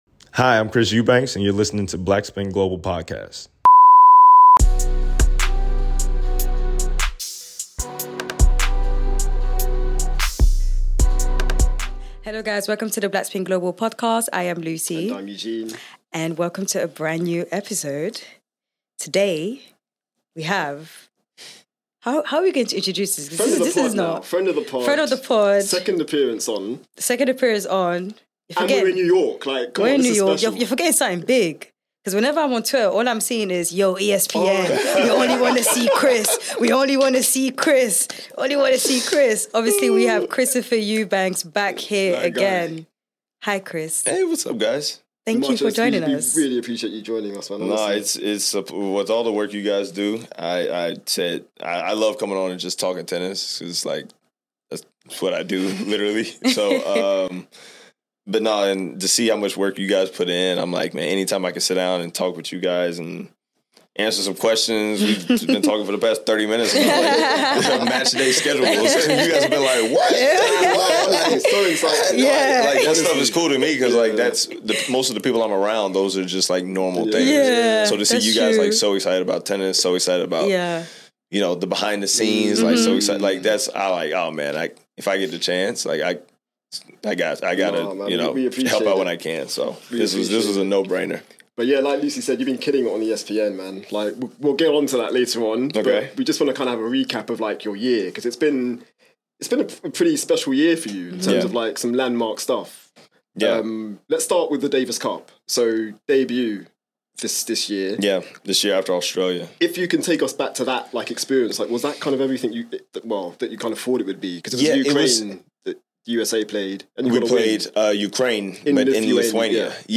Christopher Eubanks Interview